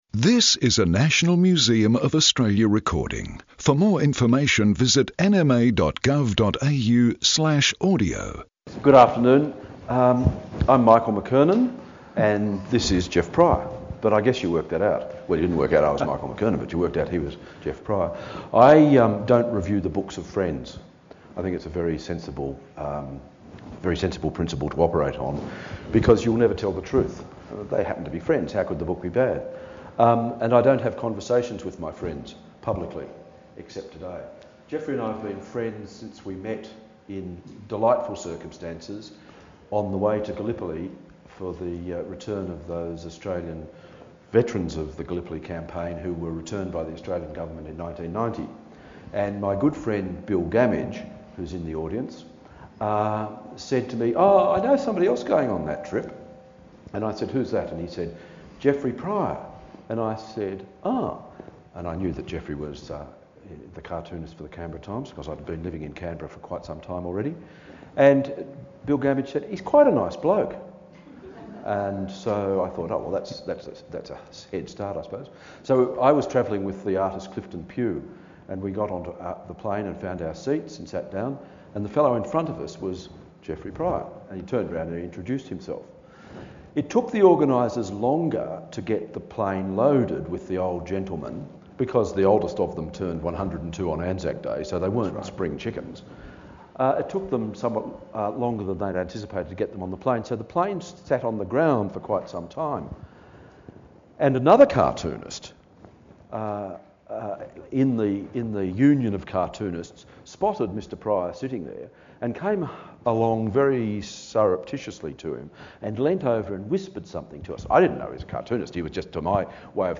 Animated conversation